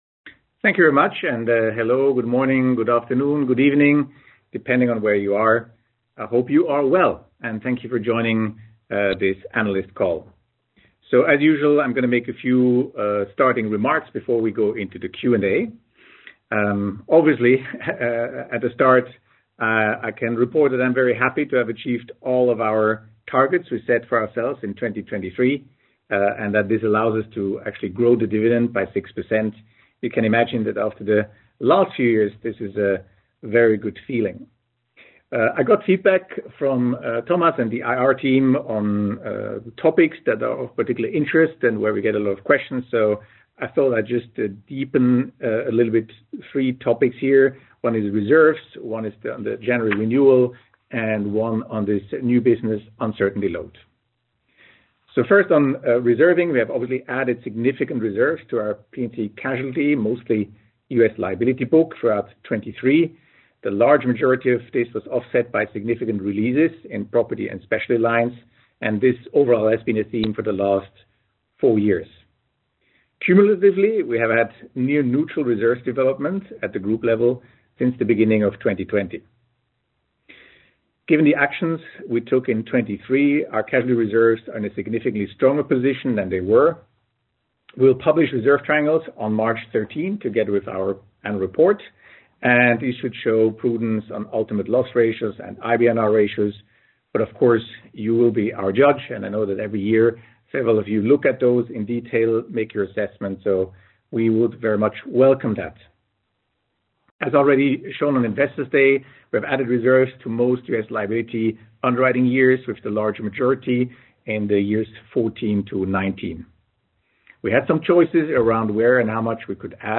fy-2023-call-recording.mp3